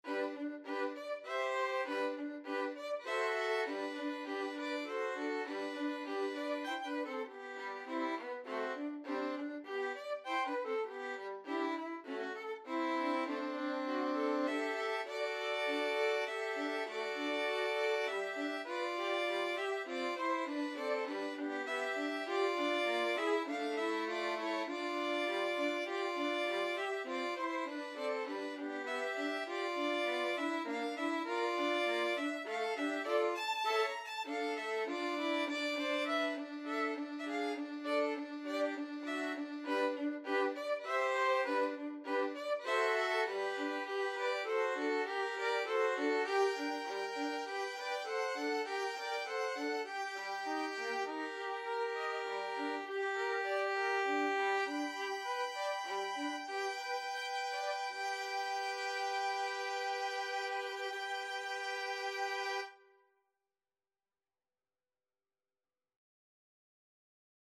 G major (Sounding Pitch) (View more G major Music for Violin Trio )
3/4 (View more 3/4 Music)
~ = 100 Allegretto grazioso (quasi Andantino) (View more music marked Andantino)
Violin Trio  (View more Intermediate Violin Trio Music)
Classical (View more Classical Violin Trio Music)